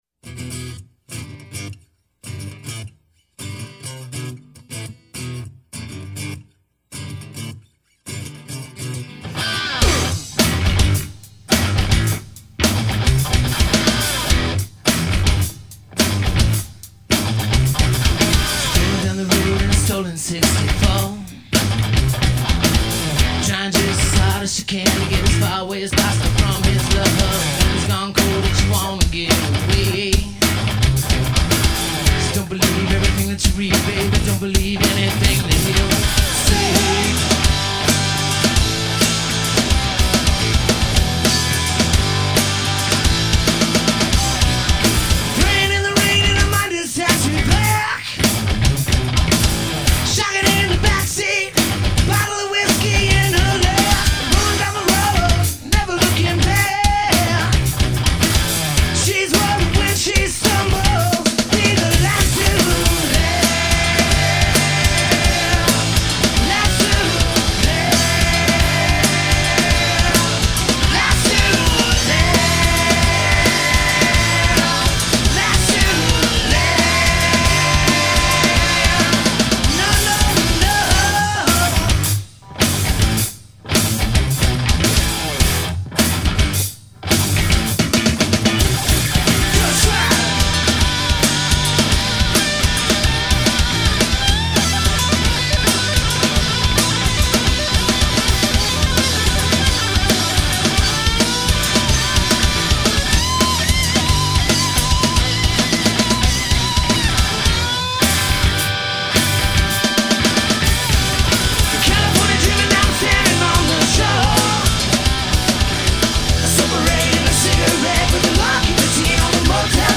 Here is a band of mine from 9 or so years ago. Recorded in a rehearsal studio with only 5 SM 58 's and a couple of Radio Shack crappies.